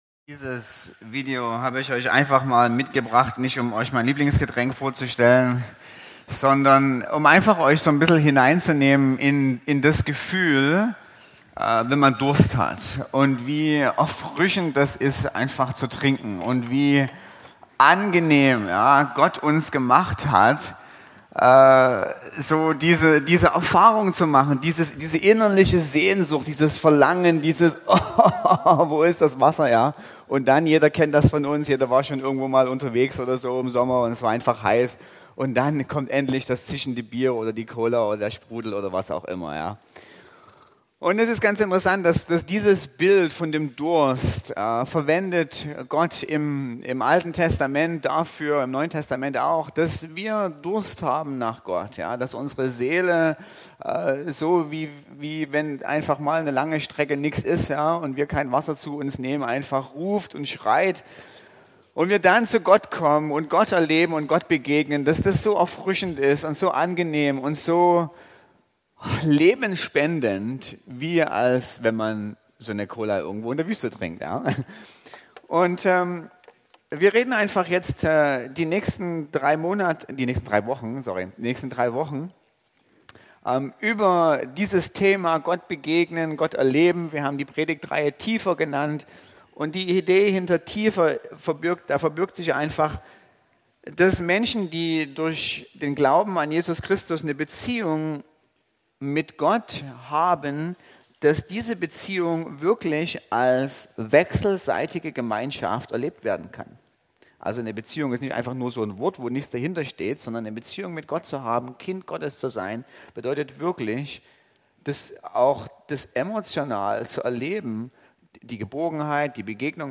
Eine predigt aus der serie "Tiefer."